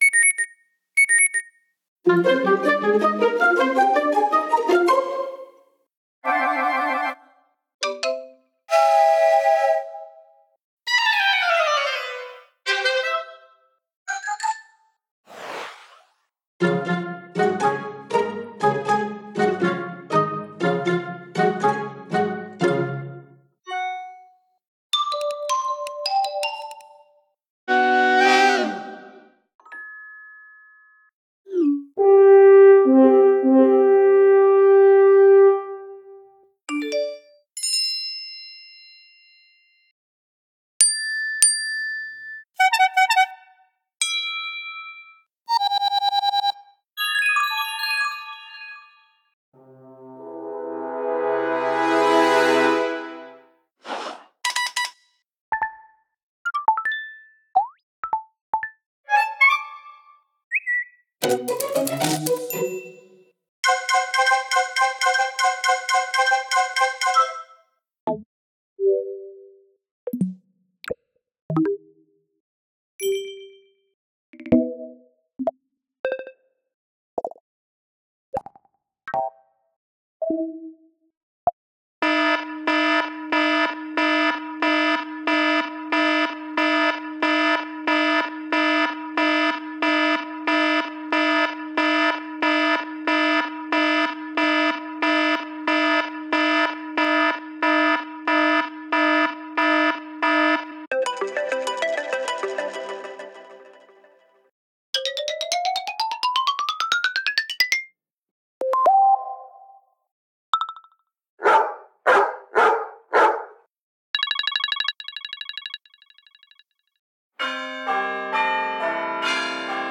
macOSsystemsounds
macOSSystemSoundsCombined.ogg